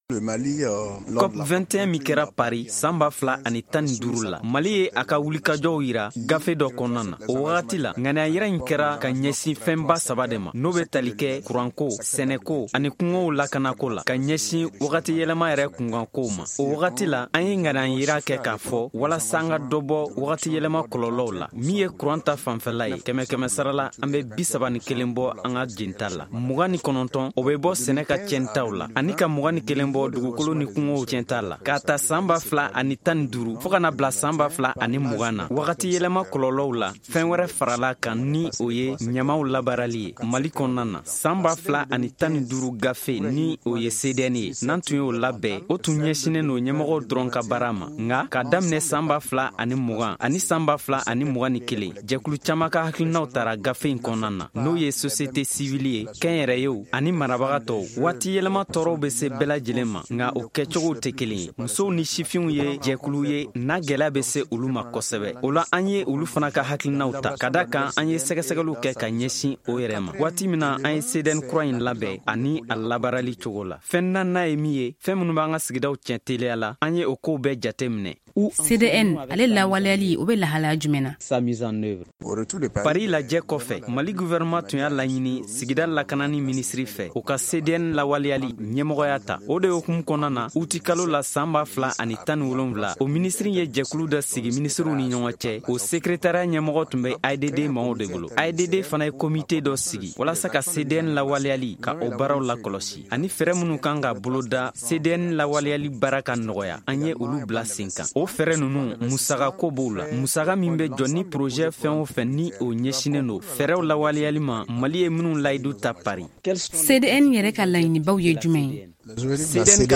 Magazine en bambara: Télécharger